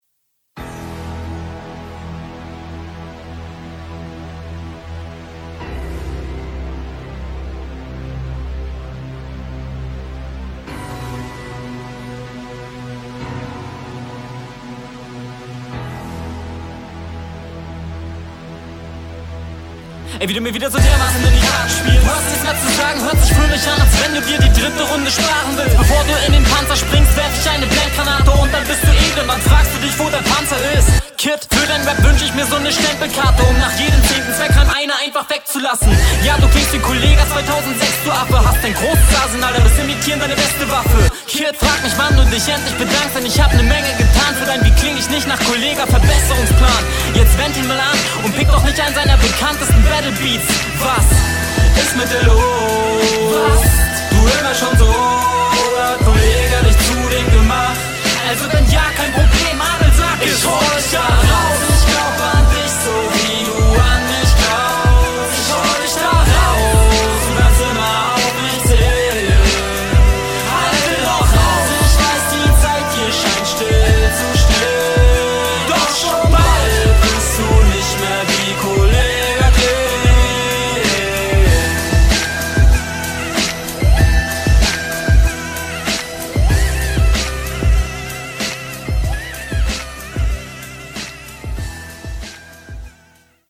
Das schnelle Intro ist mir zu schnell, aber ich feier (btw.) deine Stimme und Betonungen.